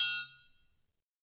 Triangle3-HitM_v2_rr2_Sum.wav